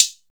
Hat (38).wav